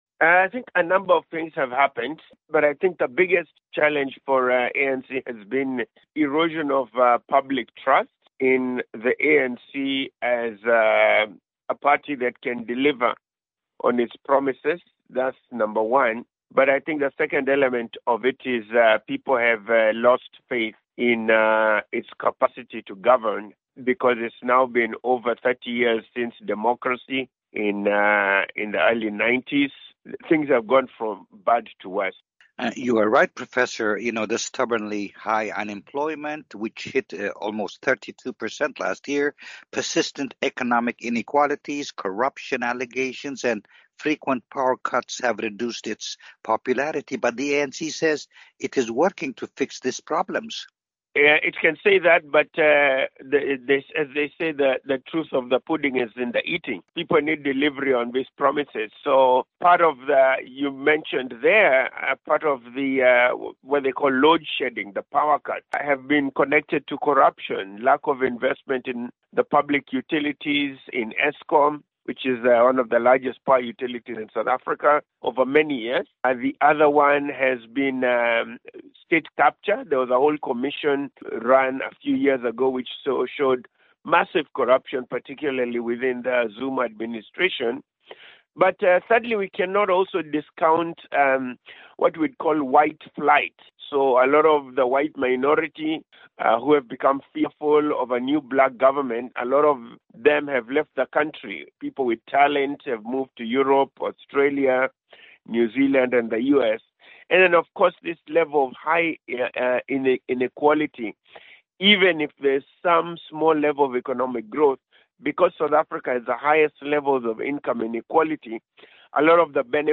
Africa News Tonight Clips